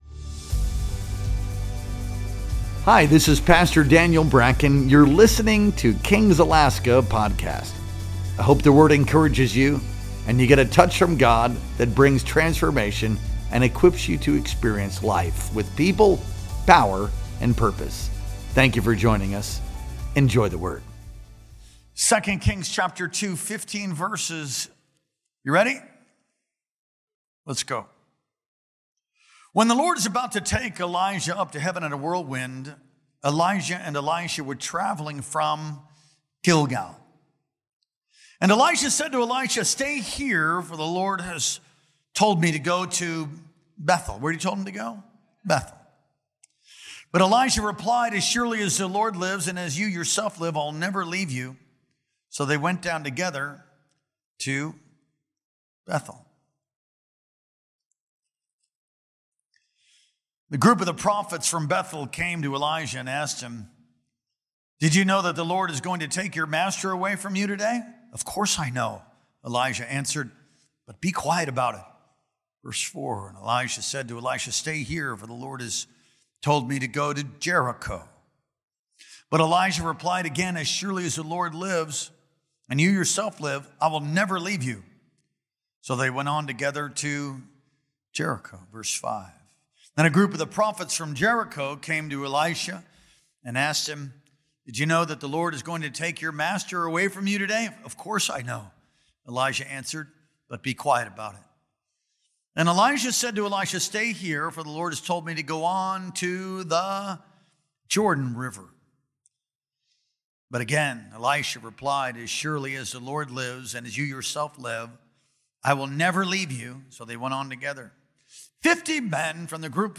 Our Sunday Morning Worship Experience streamed live on October 19th, 2025.